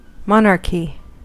Ääntäminen
Ääntäminen Tuntematon aksentti: IPA: [tʀoːn] Haettu sana löytyi näillä lähdekielillä: saksa Käännös Konteksti Ääninäyte Substantiivit 1. throne US 2. monarchy kuvaannollinen US Artikkeli: der .